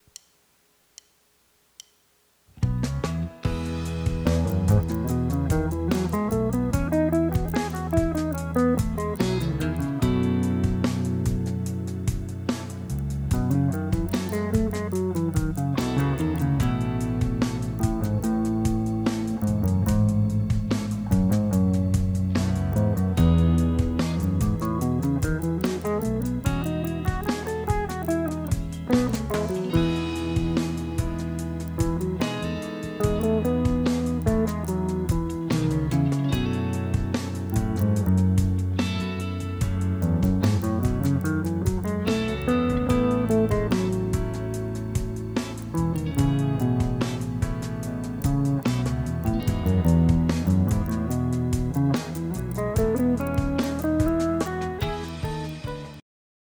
Here’s an example of an E major scale over a backing track. The only notes here are E scale notes, so it’s a limited pallet.
E-major-scale-example.mp3